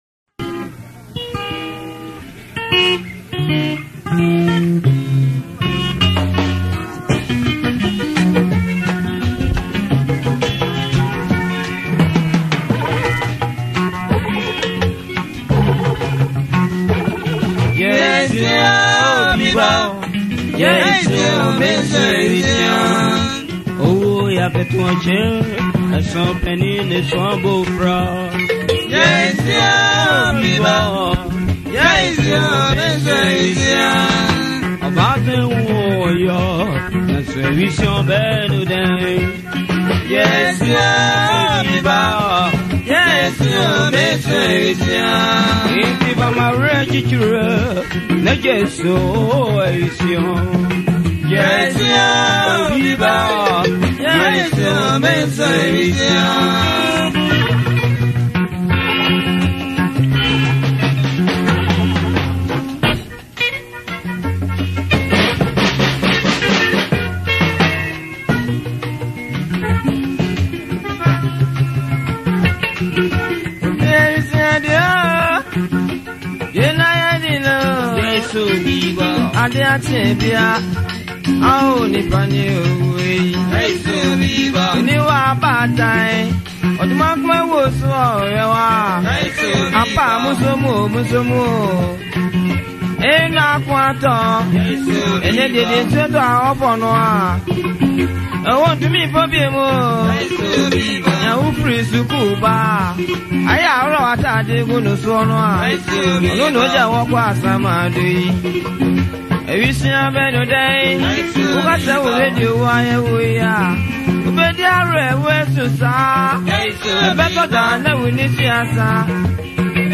an ancient highlife song